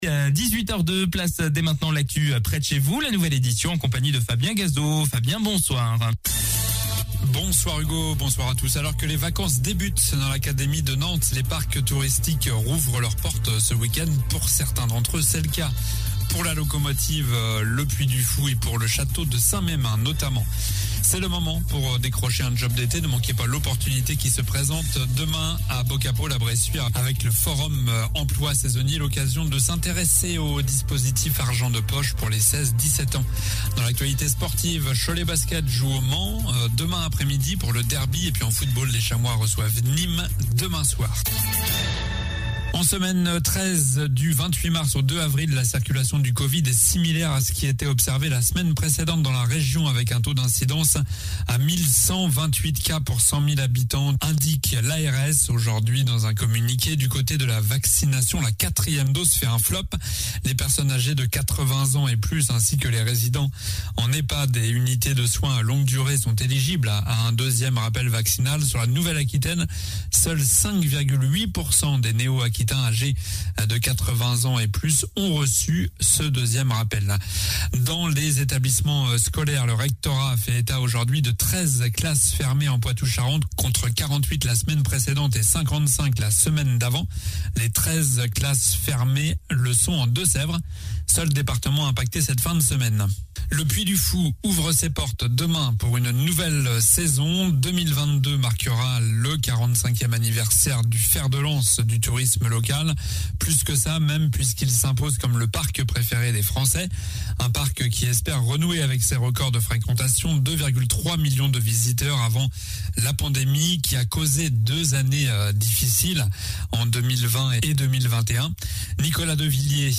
Journal du vendredi 08 avril (soir)